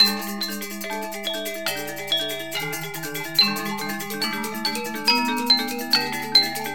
106Gamelan 048